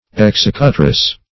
Executress \Ex*ec"u*tress\, n.
executress.mp3